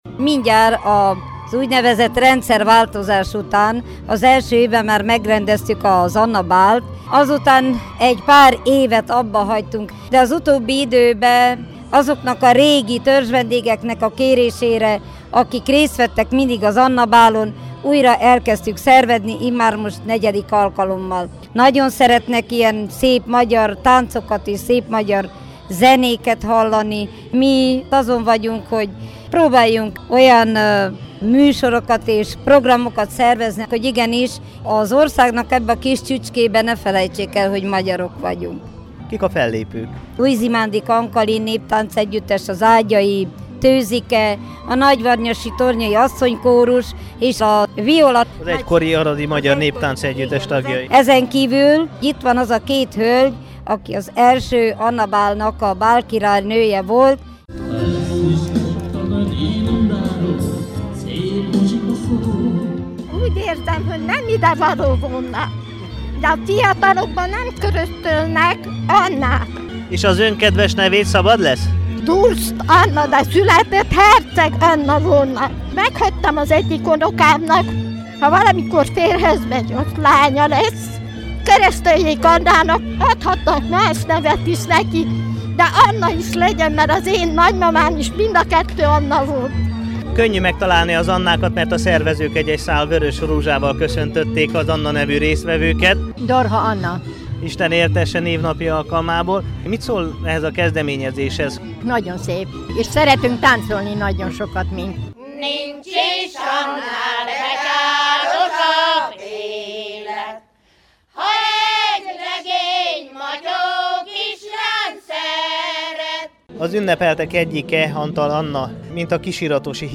anna-nap_aradon.mp3